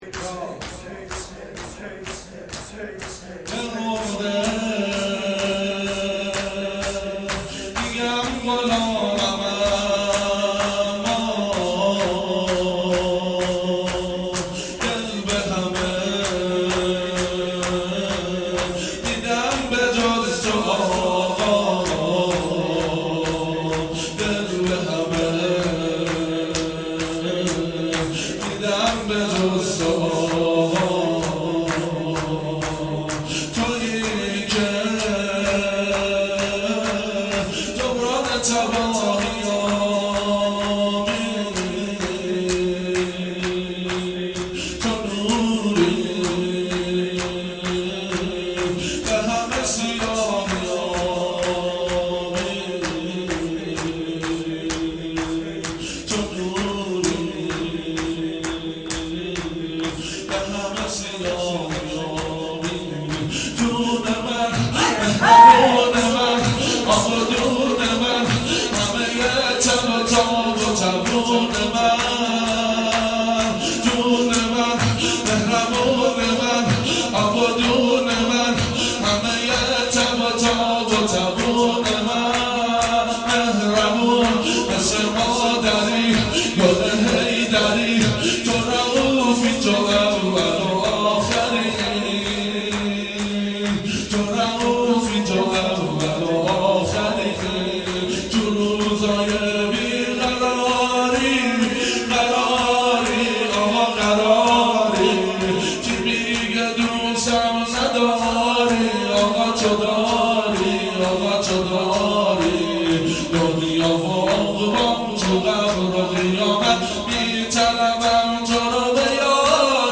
شور جدید